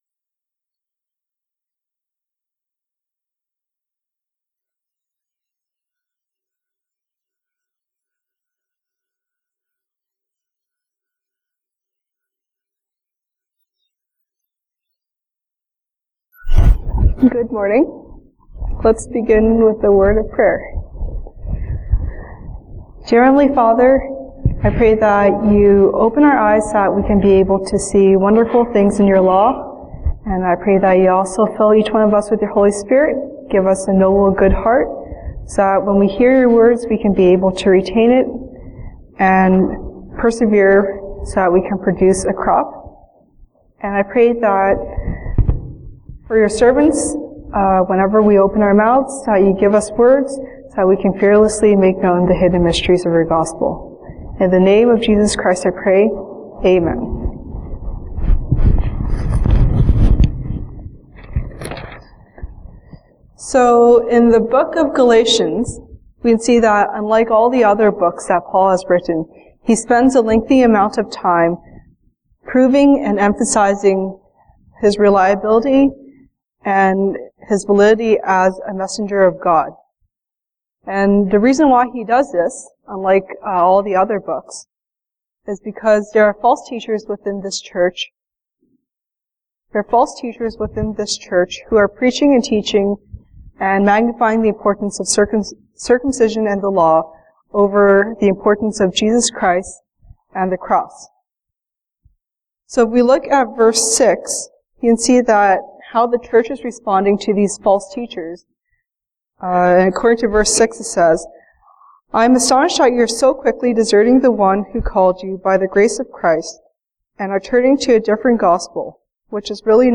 Sunday Service English